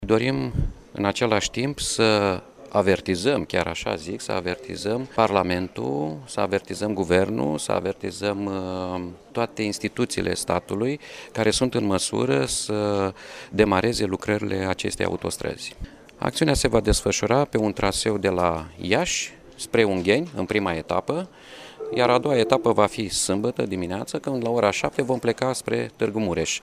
El a mai declarat postului Radio Iași că proiectul autostrăzii a fost lansat în urmă cu 11 ani şi până în prezent nu a fost concretizat nici un demers: